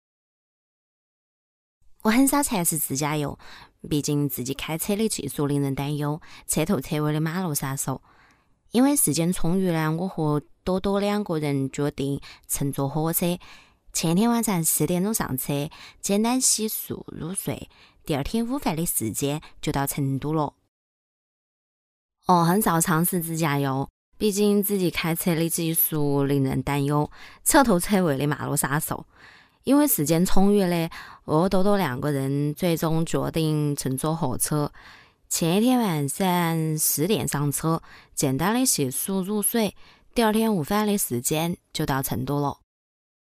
女45-方言配音【成都话 素人】
女45-方言配音【成都话 素人】.mp3